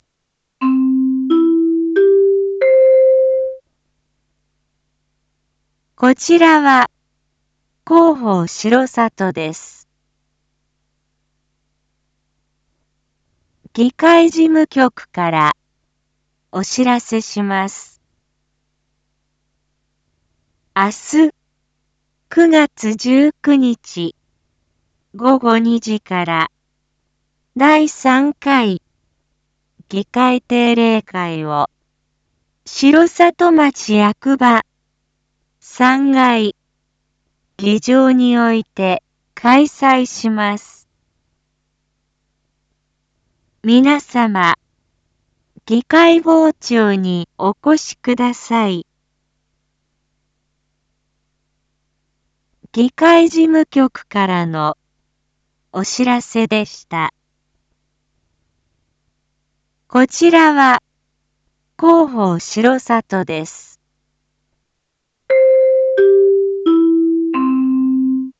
Back Home 一般放送情報 音声放送 再生 一般放送情報 登録日時：2025-09-18 19:01:10 タイトル：R7.9.19 第３回議会定例会⑤ インフォメーション：こちらは広報しろさとです。